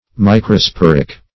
Microsporic \Mi`cro*spor"ic\